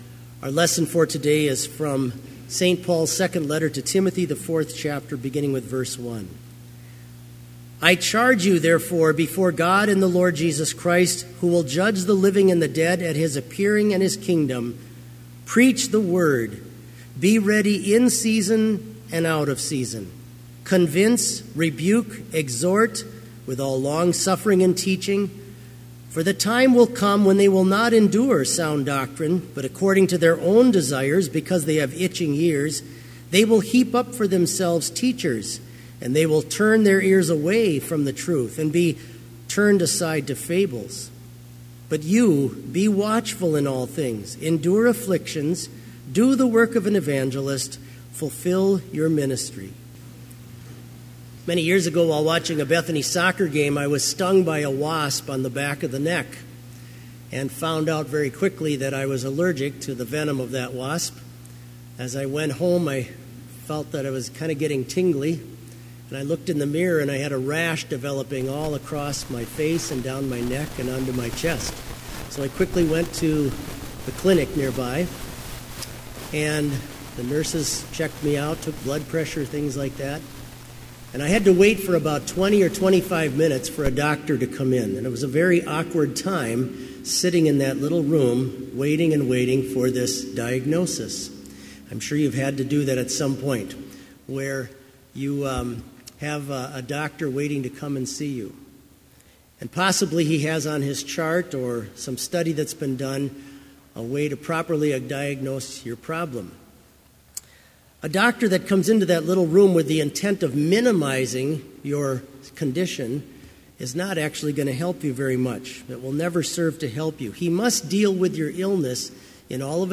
Complete Service
• Hymn 440, vv. 1, 3 & 6, O Lord, Look Down
This Chapel Service was held in Trinity Chapel at Bethany Lutheran College on Monday, November 20, 2017, at 10 a.m. Page and hymn numbers are from the Evangelical Lutheran Hymnary.